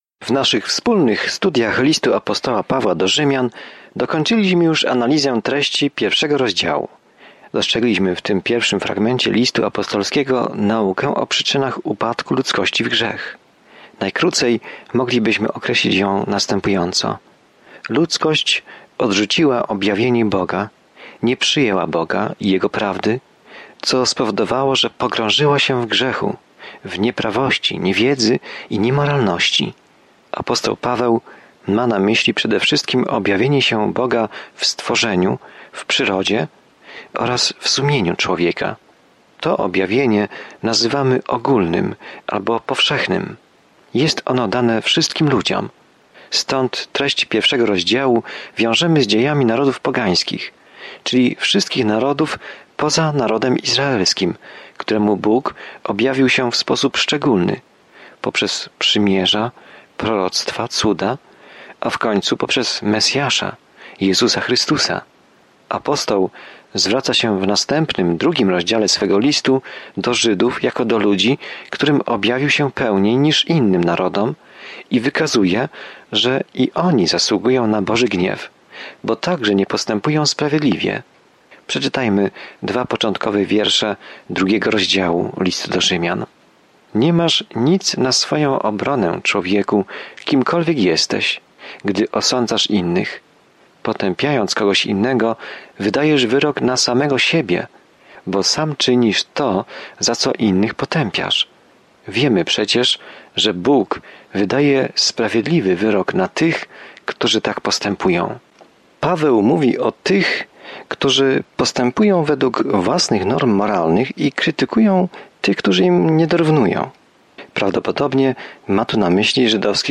Pismo Święte Rzymian 2:1-21 Dzień 5 Rozpocznij ten plan Dzień 7 O tym planie List do Rzymian odpowiada na pytanie: „Jaka jest dobra nowina?” I jak każdy może uwierzyć, zostać zbawiony, uwolniony od śmierci i wzrastać w wierze. Codzienna podróż przez List do Rzymian, słuchanie studium audio i czytanie wybranych wersetów słowa Bożego.